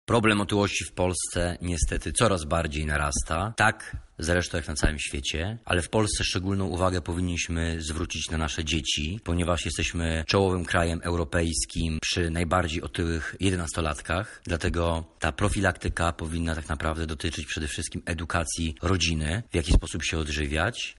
ekspert do spraw leczenia nadwagi